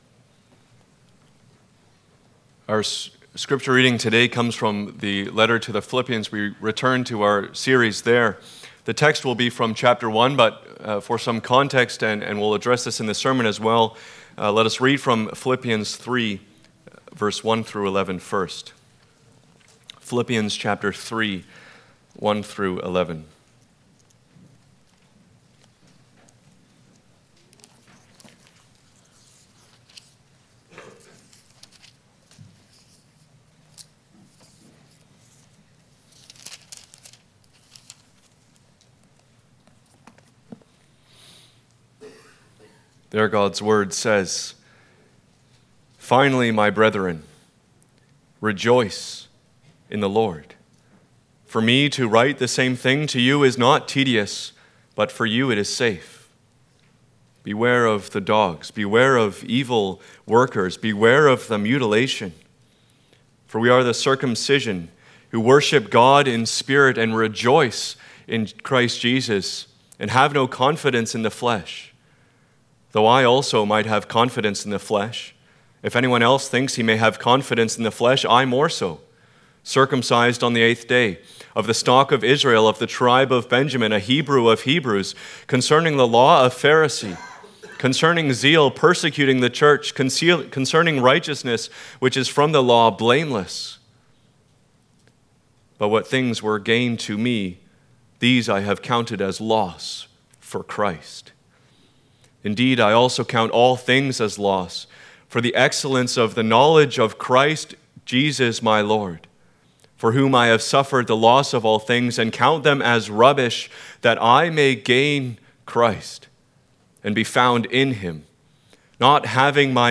Passage: Philippians 1:19-26 Service Type: Sunday Morning « O Lord our Lord hallowed by Your name The Prayer not heard at Bable “your kingdom come” »